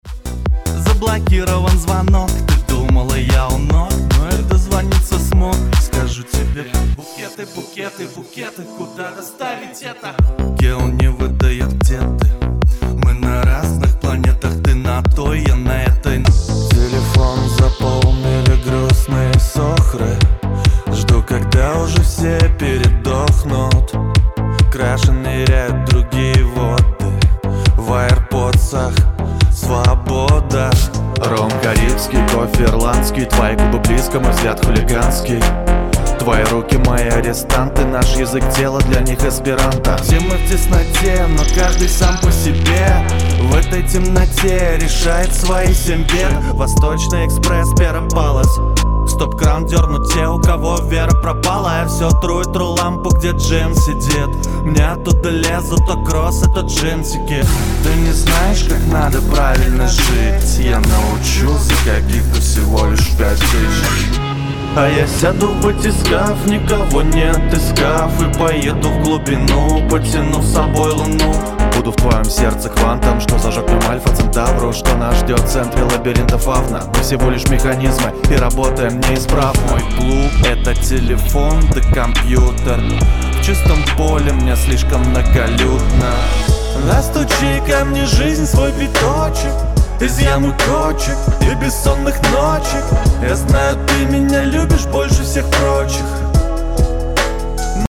Муж, Рэп/Молодой
Условия записи: Полностью оборудованная и изолированная студийная кабина, микрофон Audio-Technica АТ4040 + аудио интерфейс PreSonus Studio 192.